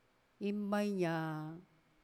次に、同じ単語を今度はセンテンスの中の環境で聞いてみてください。
また、今回の我々のデータは、宮古語池間方言を話す一部の地域の話者（西原地区の話者）、しかも７０歳以上の話者のデータしか扱っていないことも特記に値するでしょう。
sea_005_KA_sentence.wav